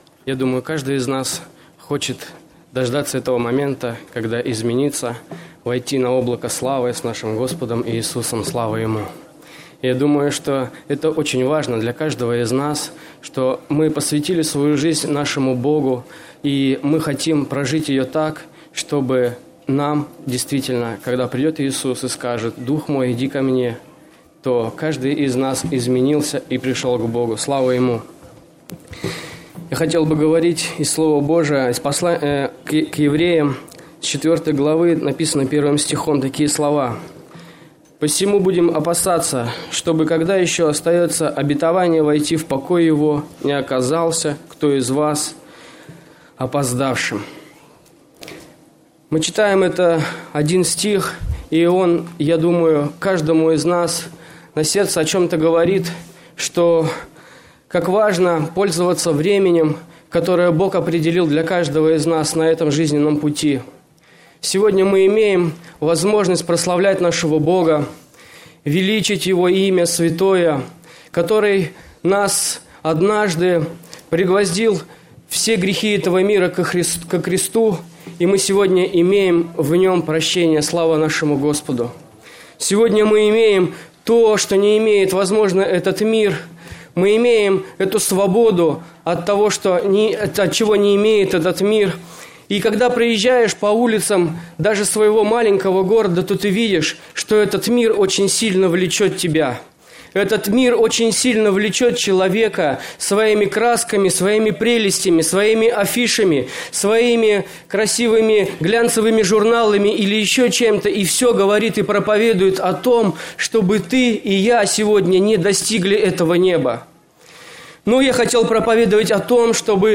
Богослужение 07.03.2010 mp3 видео фото
Проповедь